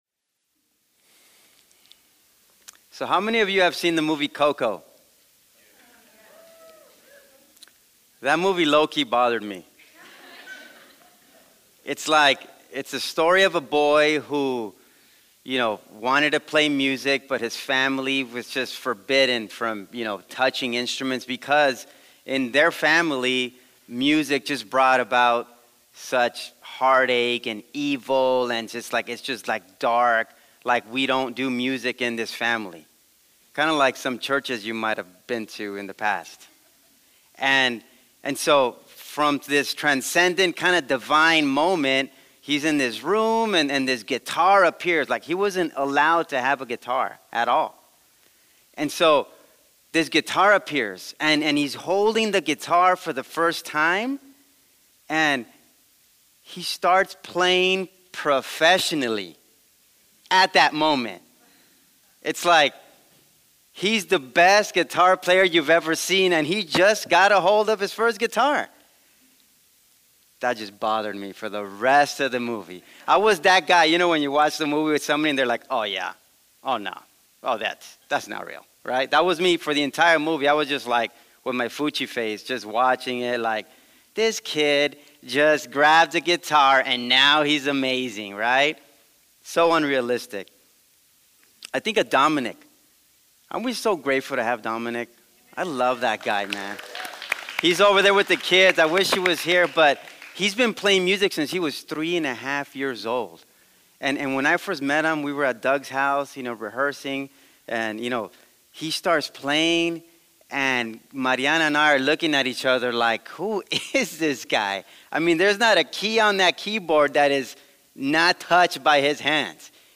A weekly podcast of messages from SouthPoint Church